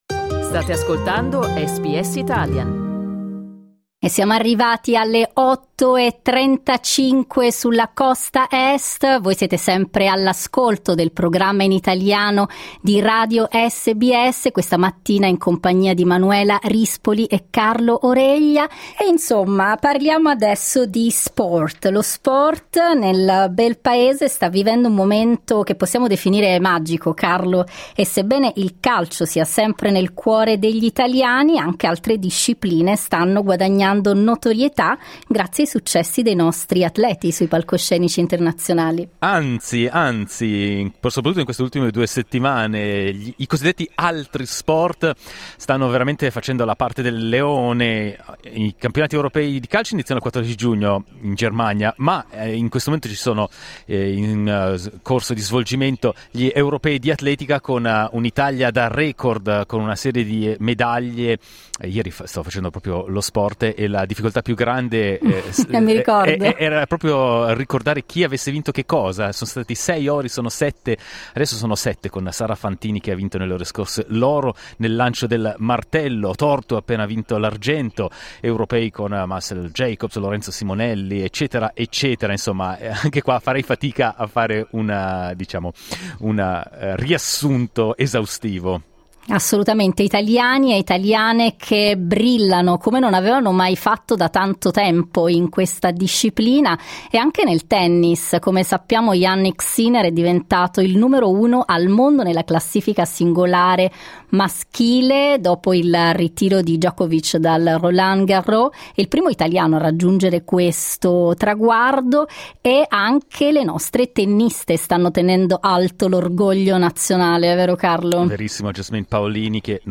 Clicca sul tasto "play" in alto per ascoltare il dibattito Questi successi si riflettono anche in un rinnovato interesse tra giovani e giovanissimi per sport che non siano il calcio?